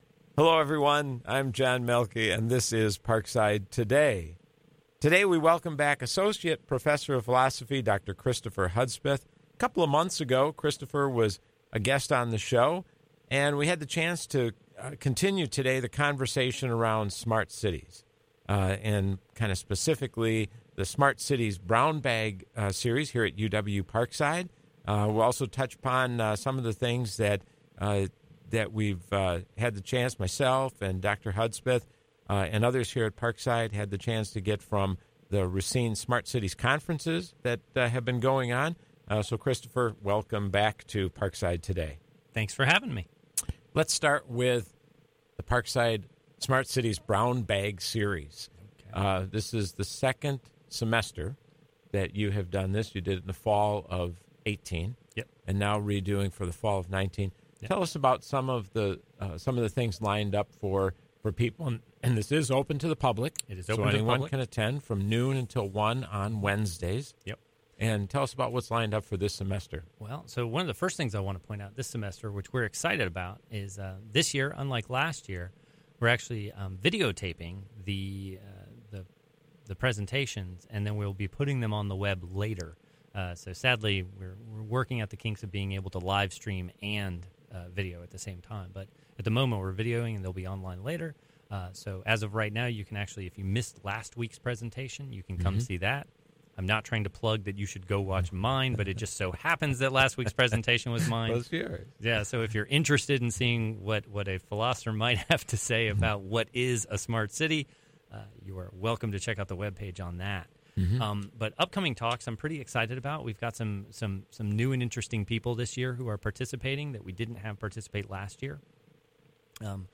This show originally aired on Tuesday, September 24, at 4 p.m. on WIPZ 101.5 FM.